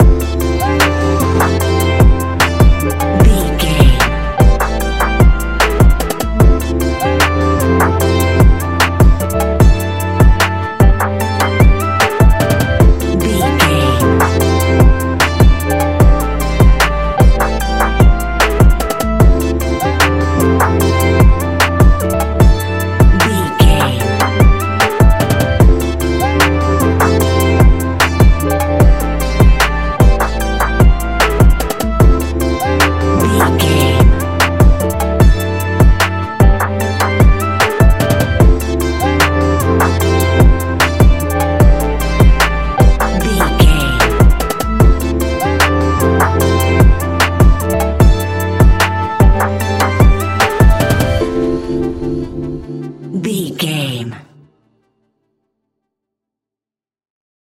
Ionian/Major
laid back
Lounge
sparse
new age
chilled electronica
ambient
atmospheric
instrumentals